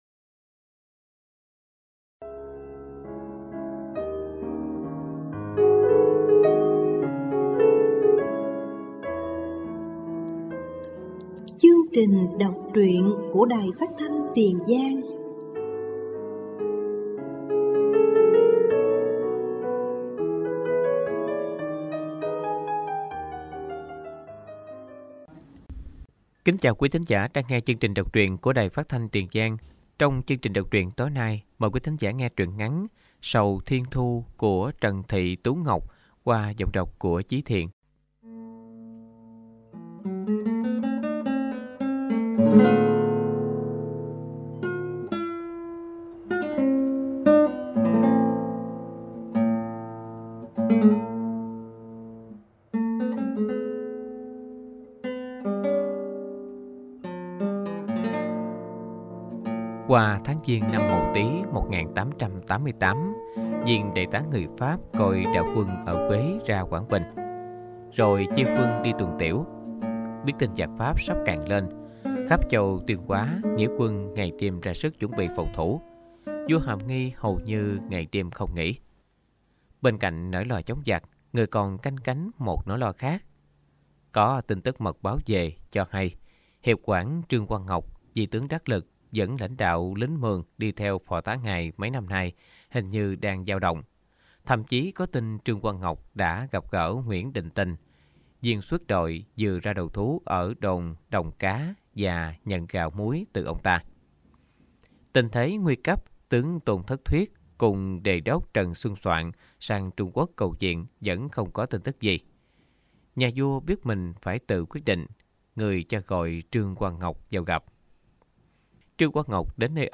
Đọc truyện “Sầu thiên thu”